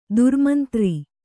♪ durmantri